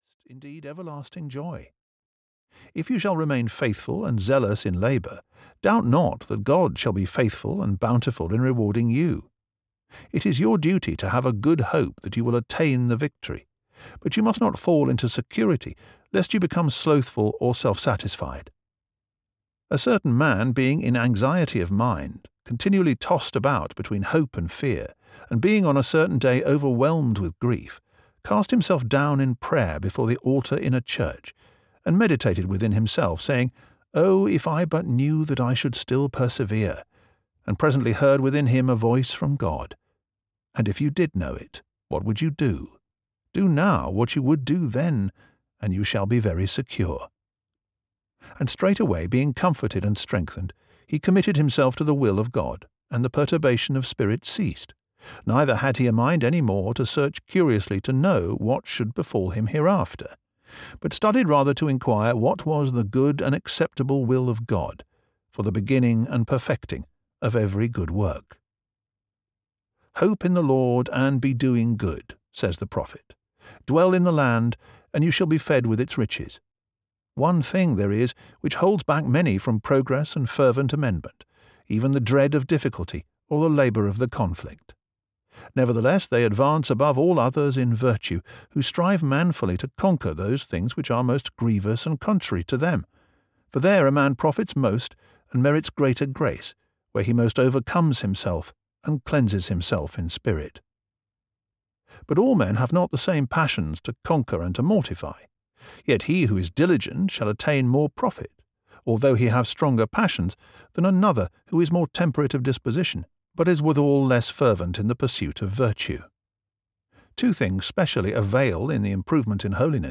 The Imitation of Christ (EN) audiokniha
Ukázka z knihy